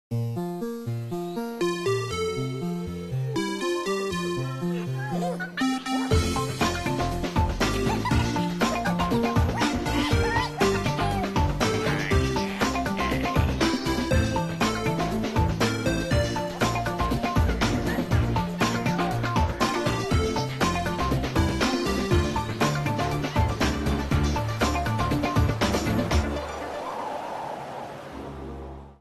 The music that plays in the opening Birthday Party scene.